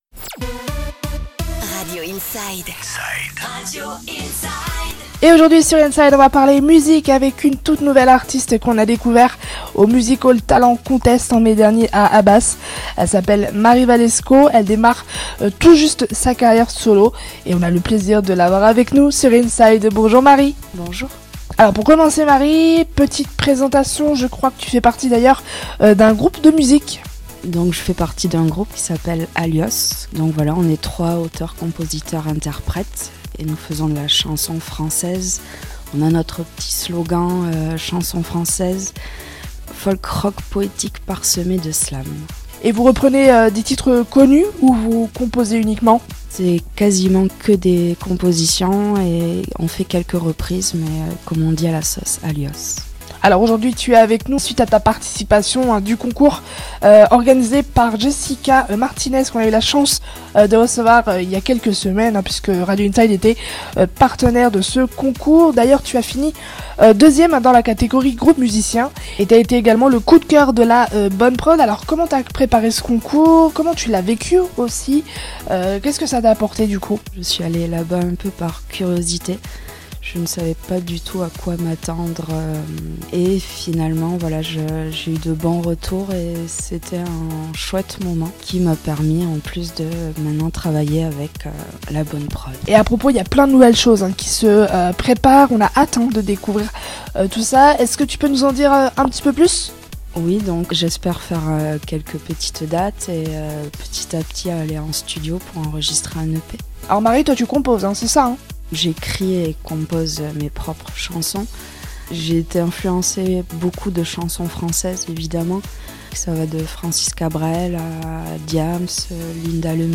Slameuse